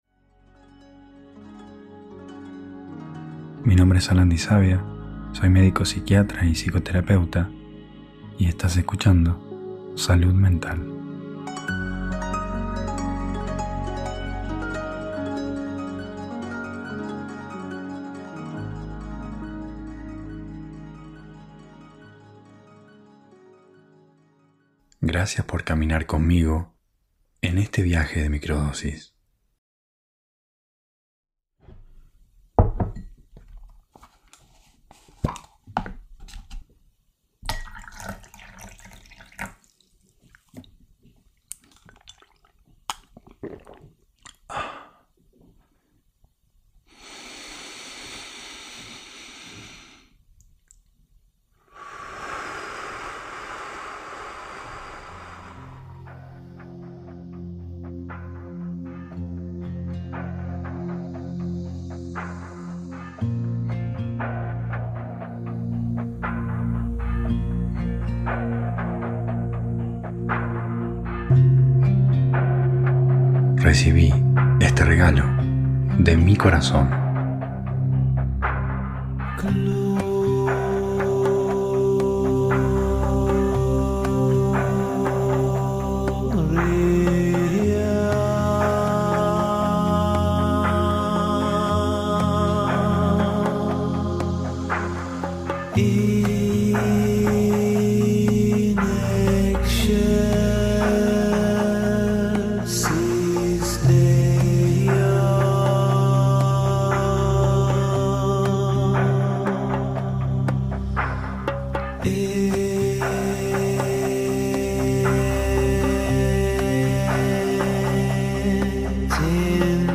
Ritual de Microdosis guiado - Pensamientos